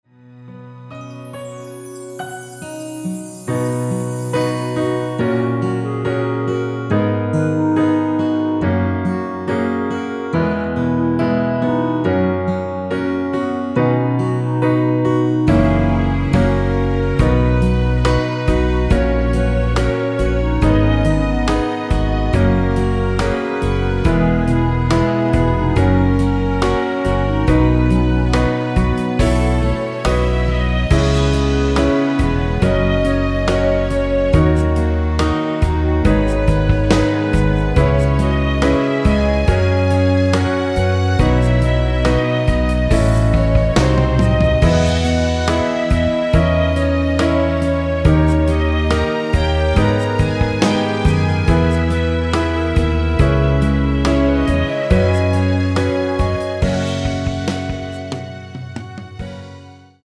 원키 멜로디 포함된 MR 입니다.(미리듣기 참조)
멜로디 MR이라고 합니다.
앞부분30초, 뒷부분30초씩 편집해서 올려 드리고 있습니다.
중간에 음이 끈어지고 다시 나오는 이유는